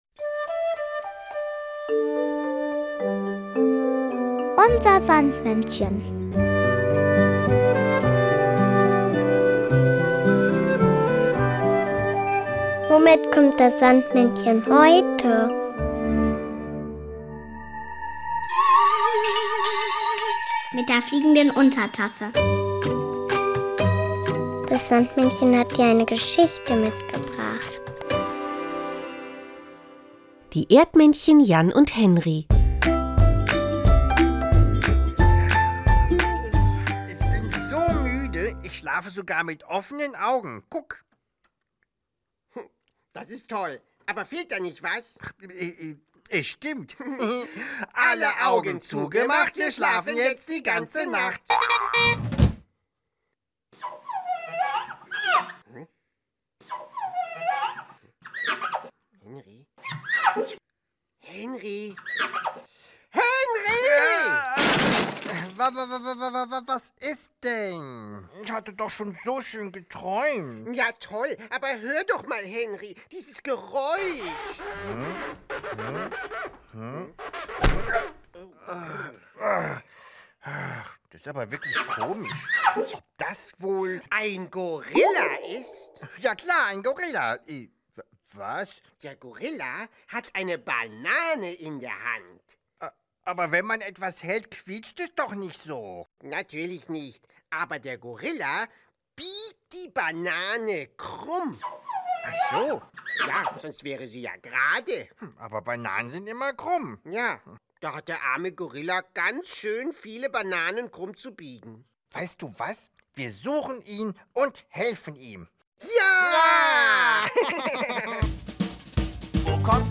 Geschichte mitgebracht, sondern auch noch das Kinderlied